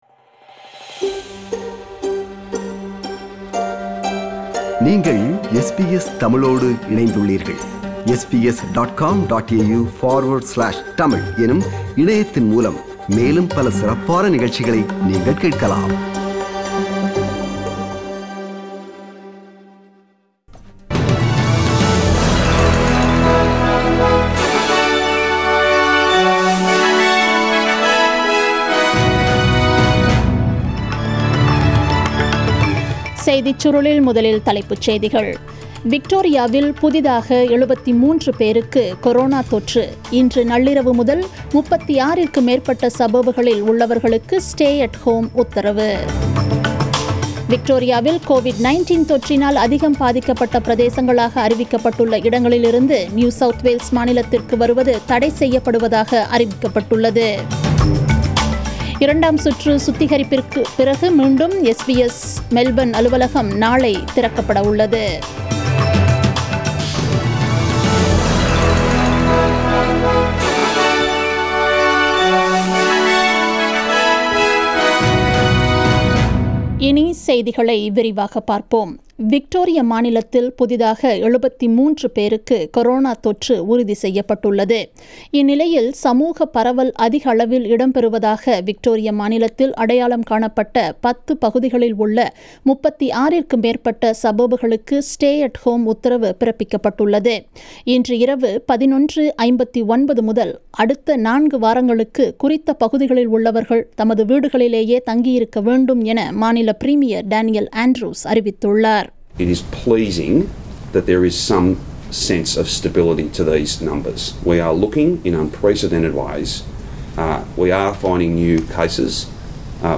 The news bulletin aired on 01 July 2020 at 8pm